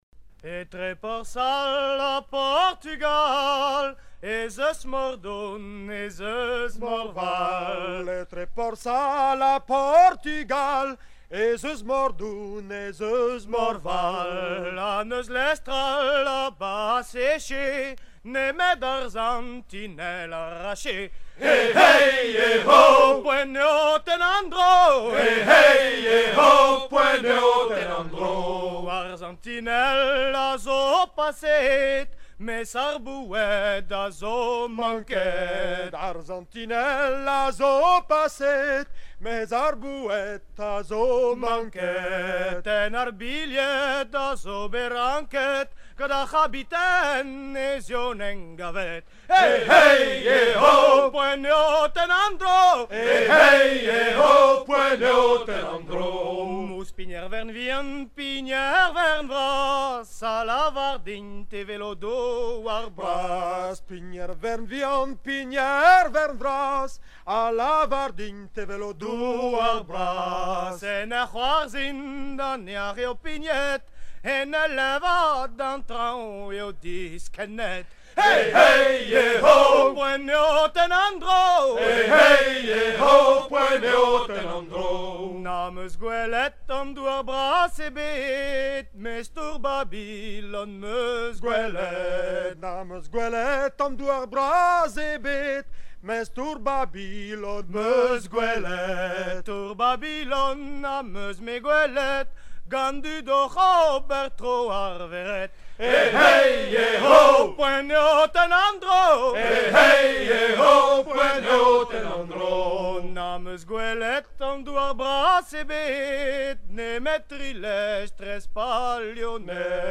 Version recueillie dans les années 1970
Chants de marins traditionnels